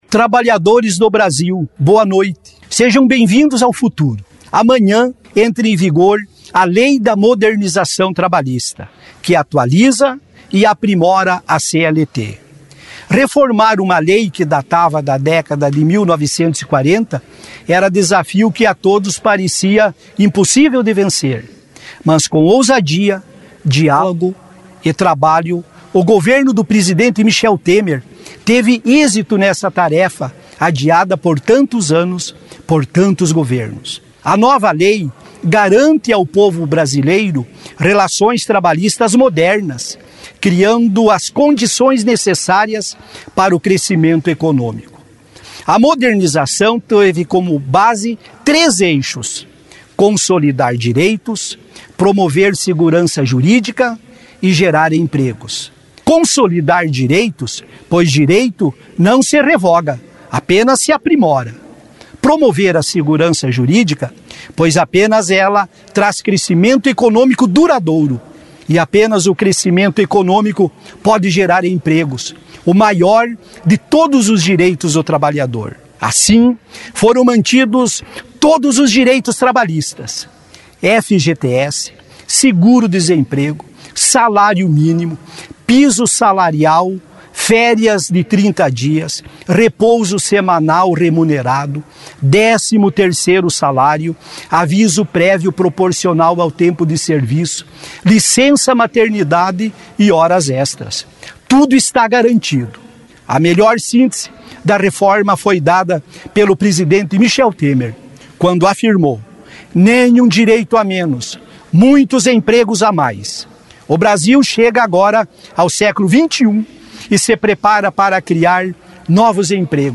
Pronunciamento do Ministro do Trabalho Ronaldo Nogueira